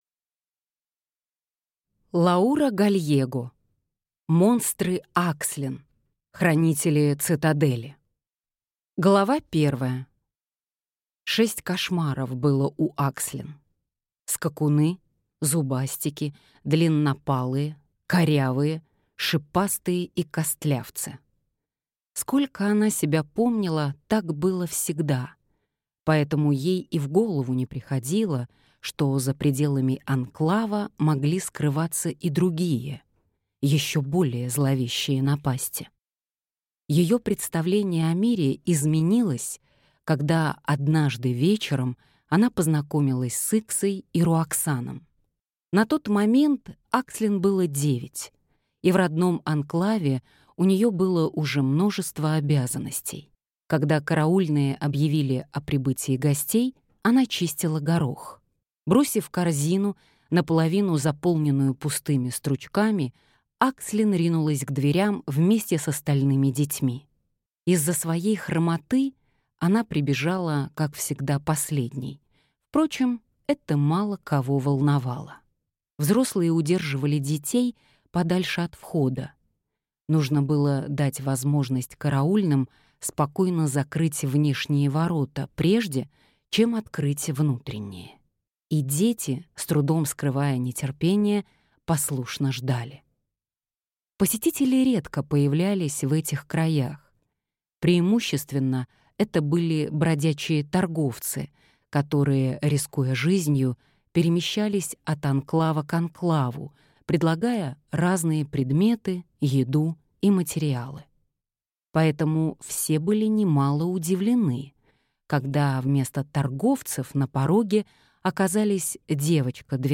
Аудиокнига Монстры Акслин | Библиотека аудиокниг